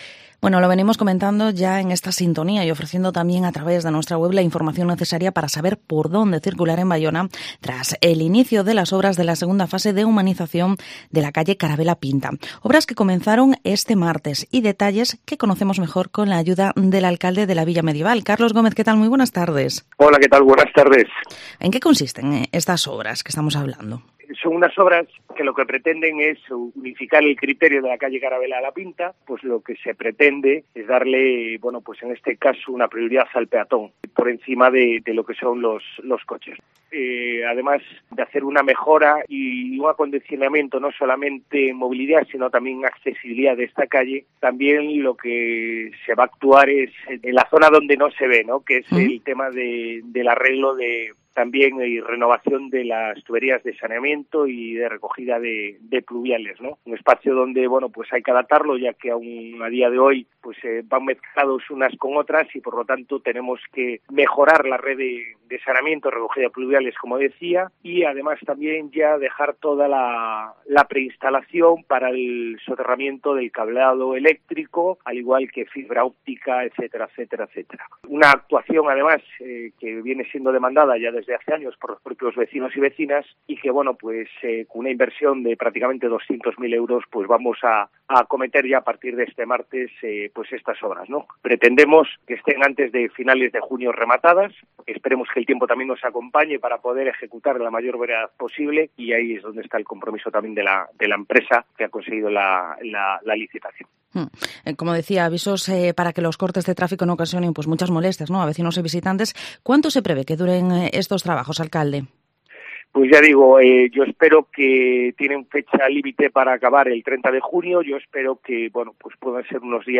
Entrevista al Alcalde de Baiona, Carlos Gómez